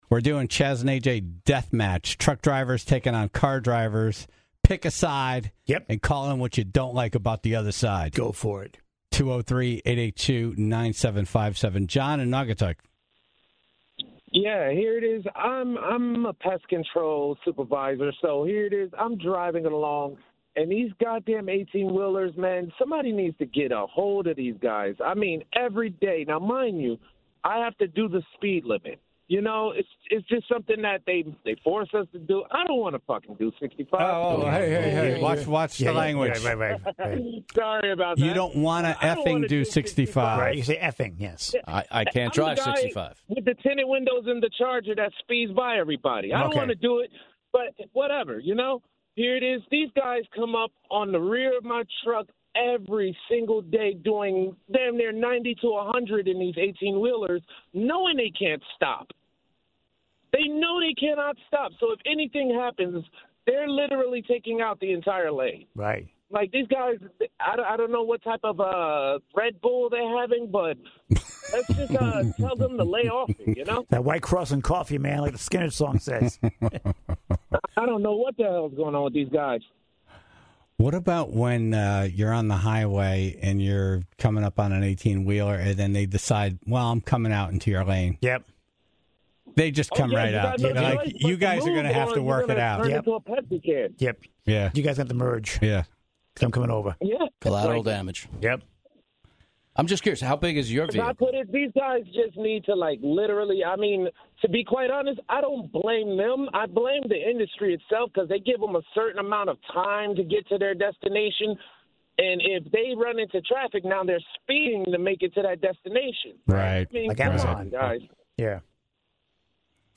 The phone lines were open for truckers and 4-wheel drivers to call in to complain about the other. According to the calls, it certainly sounds like car drivers lost in a landslide.